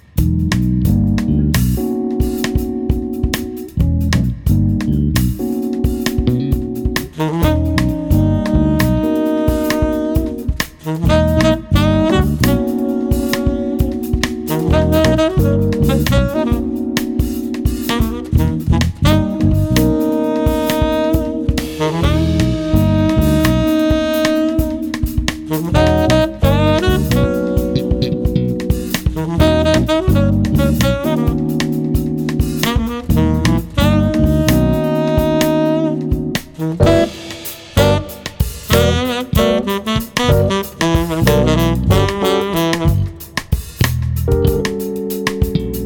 saxes
bass
drums & percussion
pianos and composer